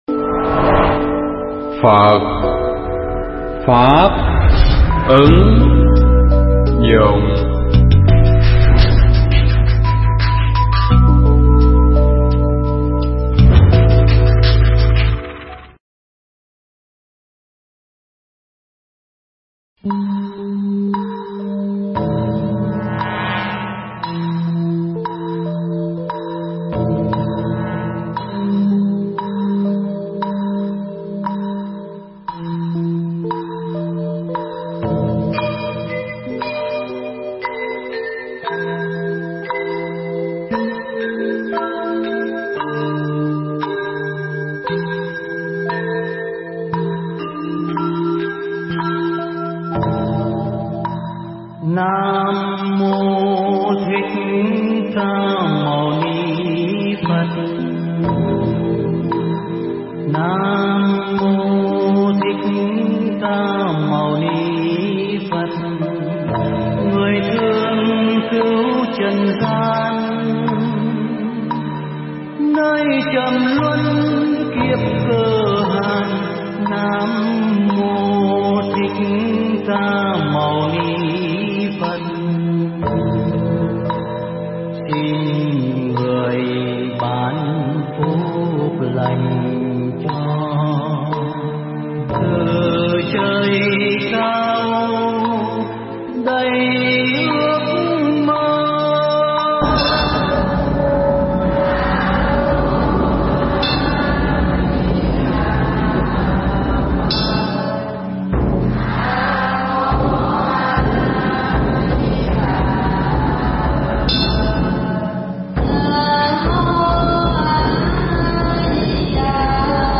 Pháp thoại Đức Phật Là Ai
trong khoá tu Phật Thất tại chùa Bửu Quang - quận 8, Tp. HCM